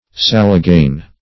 Meaning of salagane. salagane synonyms, pronunciation, spelling and more from Free Dictionary.
Search Result for " salagane" : The Collaborative International Dictionary of English v.0.48: Salagane \Sal"a*gane\, n. [From the Chinese name.]